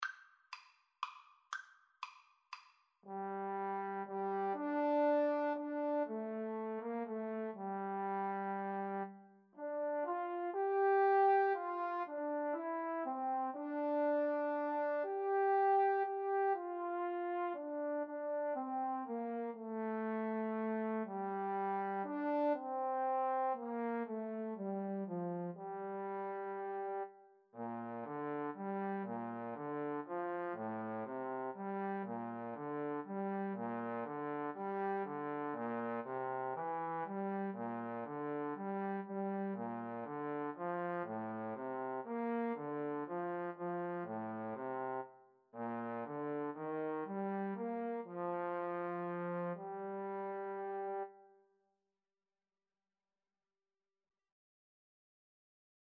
Trombone 1Trombone 2
Lento =120
3/4 (View more 3/4 Music)